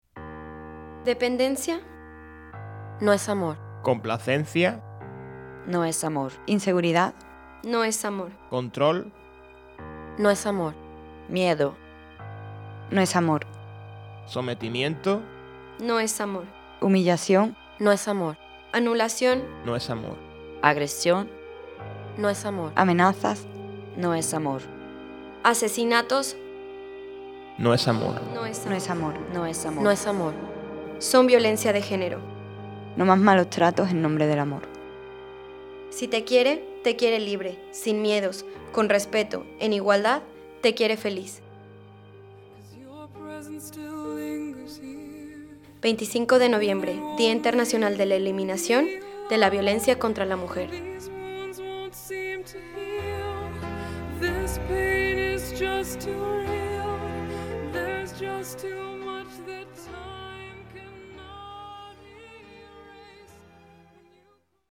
Cuña Día Internacional de la Eliminación de la Violencia contra la Mujer por Mujeres entre Mundos Radio Andalucía – Asociación Mujeres Entre Mundos